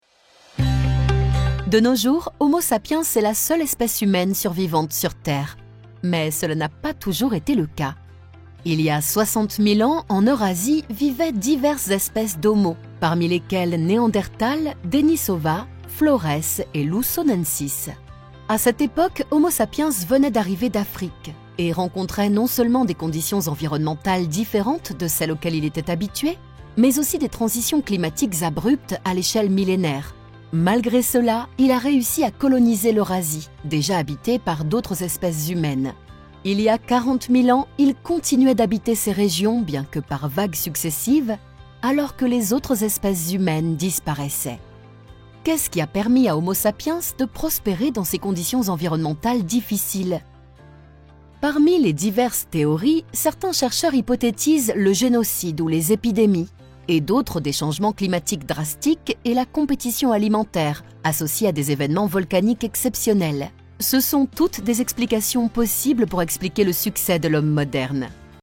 Accessible, Warm, Playful, Natural, Friendly
Explainer